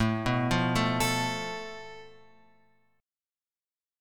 Asus2#5 chord {5 2 3 4 x x} chord